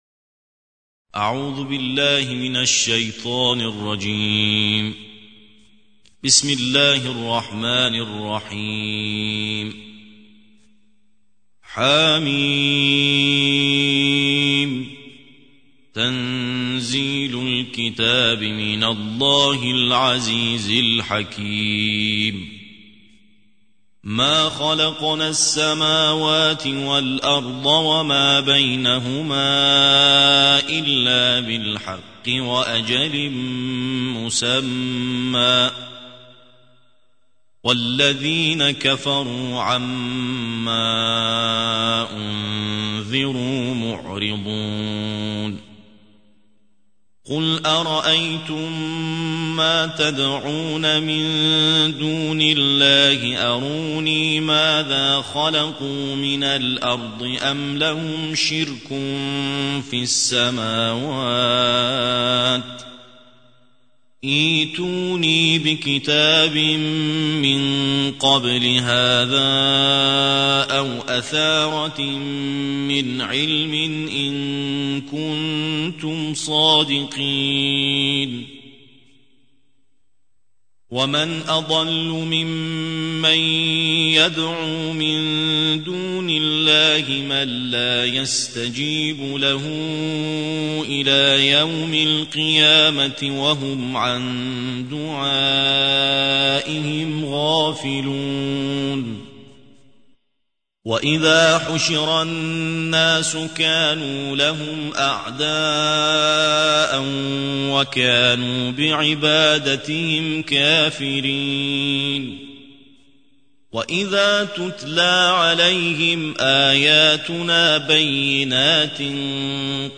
الجزء السادس والعشرون / القارئ